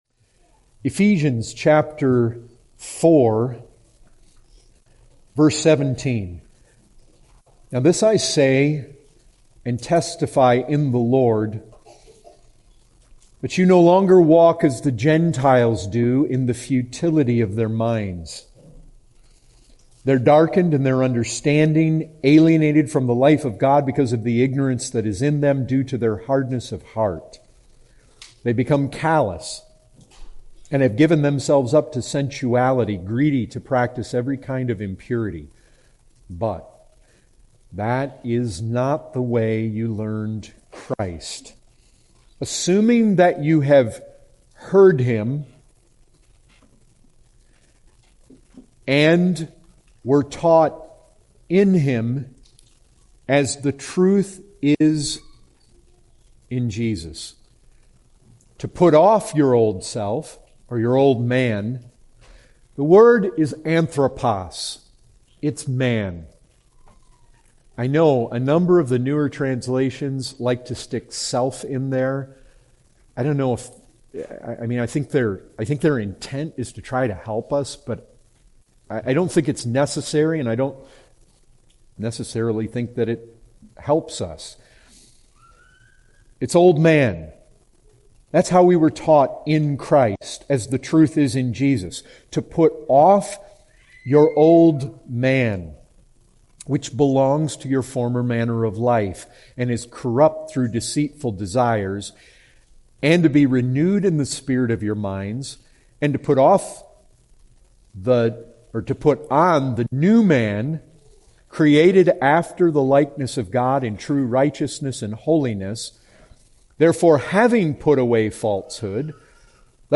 2019 Category: Full Sermons Topic